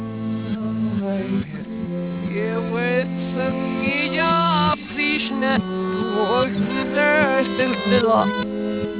Backwards